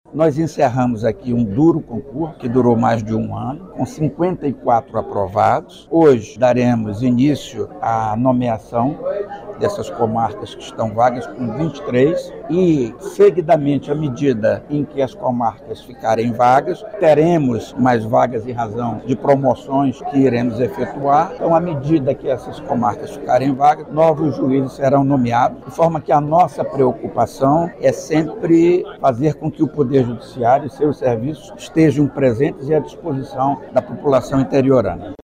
O evento foi realizado no Auditório do Centro Administrativo do TJAM, localizado na zona Centro-Sul de Manaus.
O presidente da Corte, desembargador Jomar Fernandes, destacou que a posse dos novos magistrados representa o fortalecimento do Poder Judiciário, no interior do Amazonas.